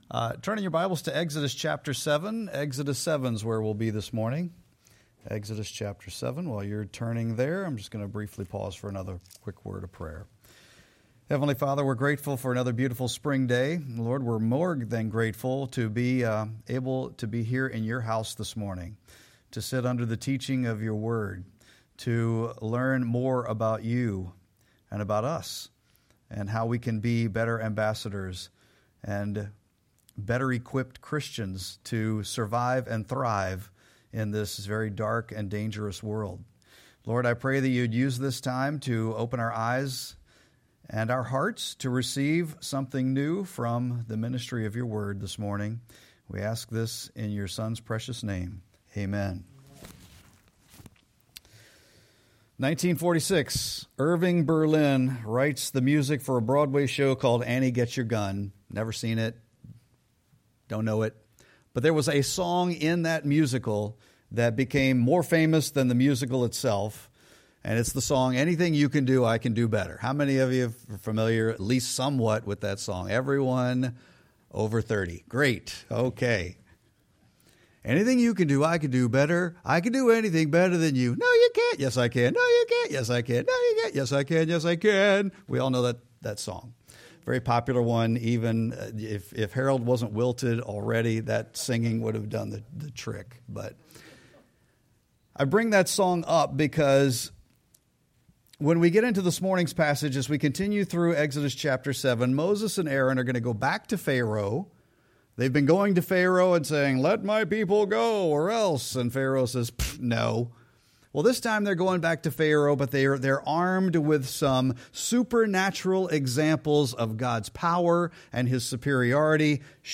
Sermon-4-19-26.mp3